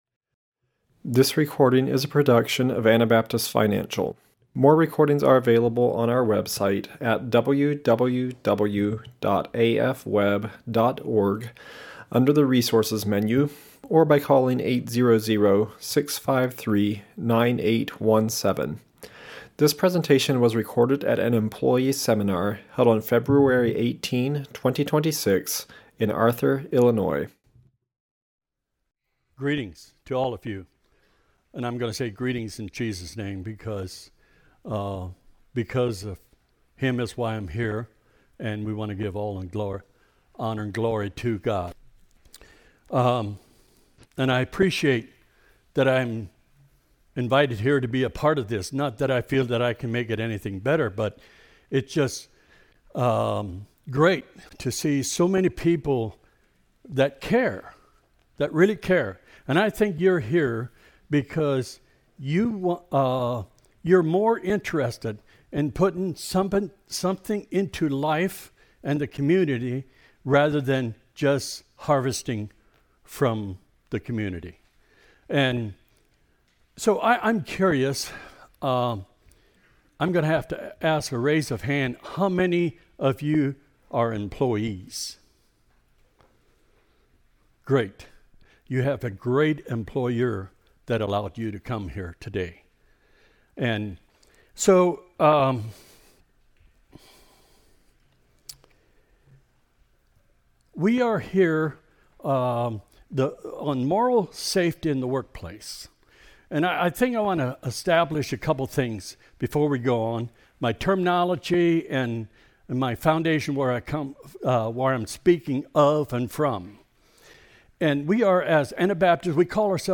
Illinois Employee Seminar 2026 / Business Leadership Proactive steps should be taken to protect moral safety.